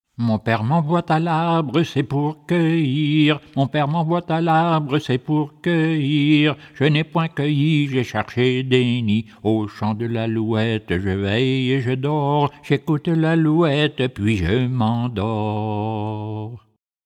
Origine : Canada (Acadie)